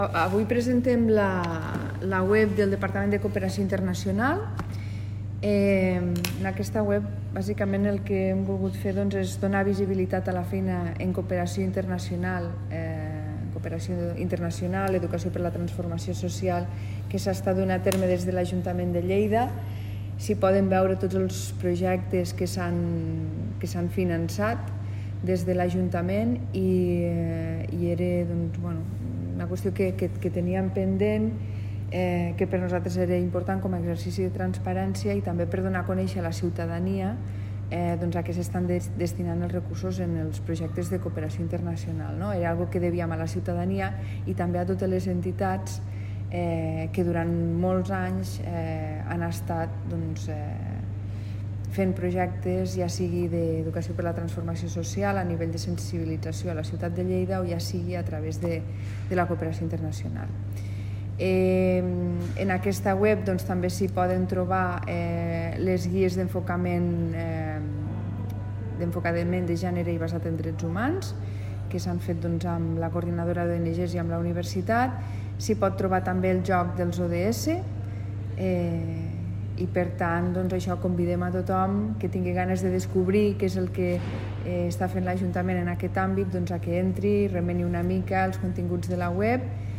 Tall de veu S. Castro